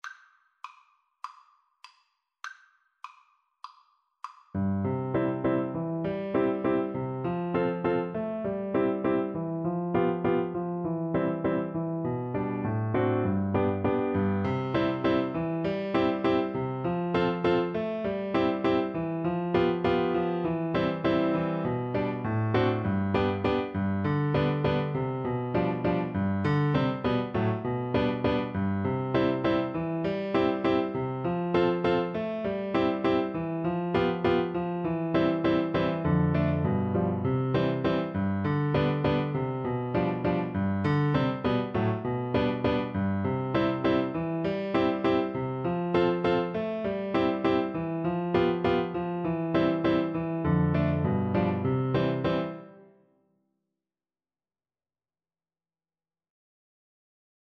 Flute
G5-C7
4/4 (View more 4/4 Music)
~ = 100 Frisch und munter
C major (Sounding Pitch) (View more C major Music for Flute )
Classical (View more Classical Flute Music)